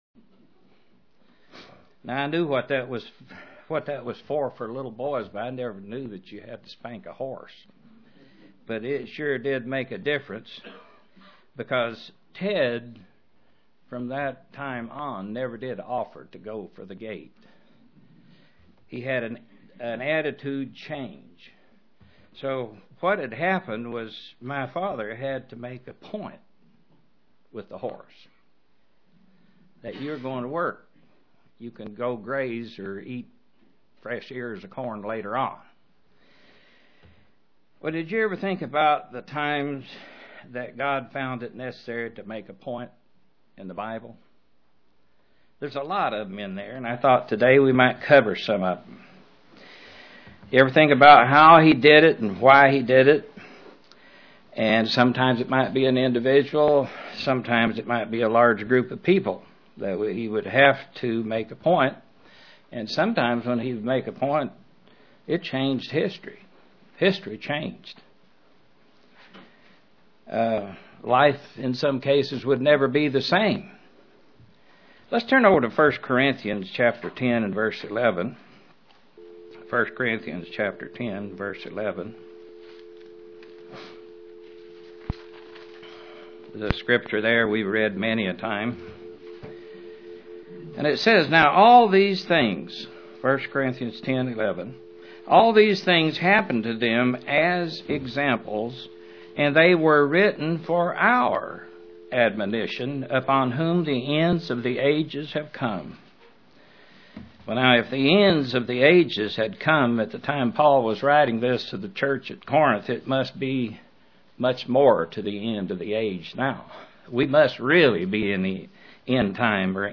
Print God never varies in His conclusion once He puts forth a plan UCG Sermon Studying the bible?